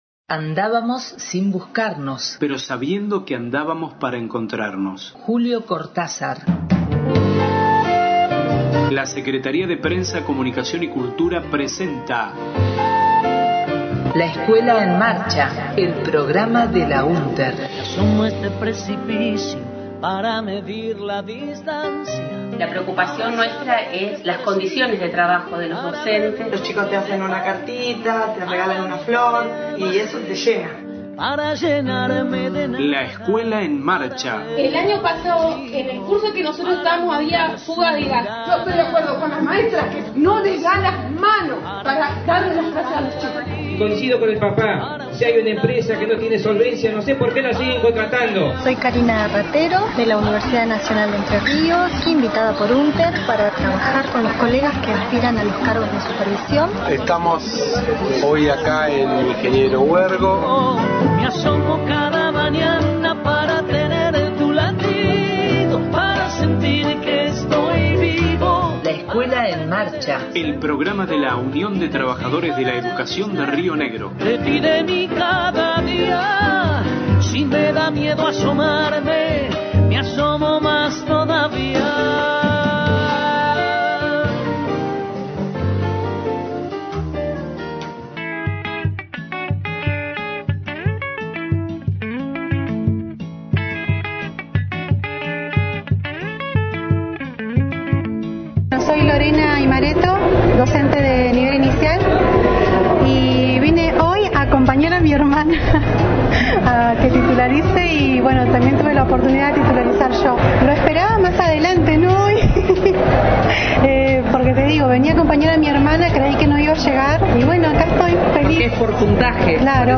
Testimonios y audios de asambleas de Titularización